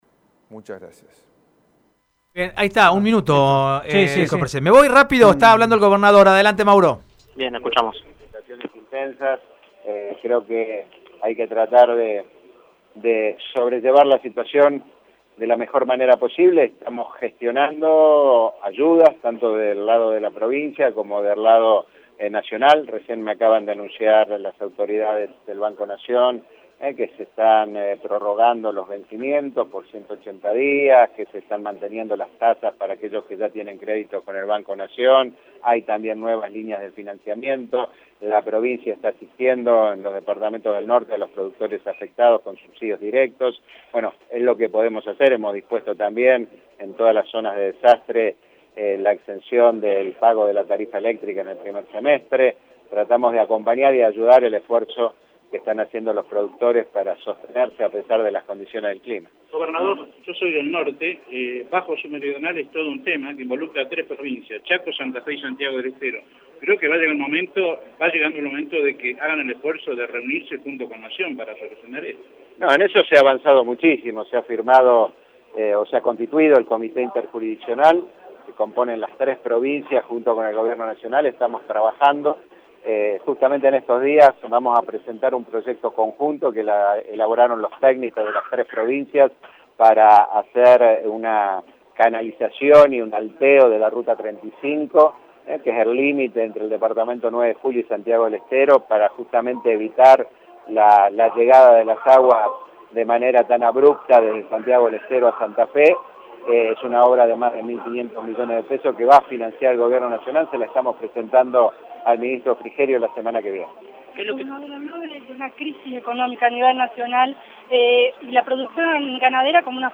En su discurso inaugural, hizo hincapié en el alto potencial productivo que tiene la provincia en cuanto a producción ganadera.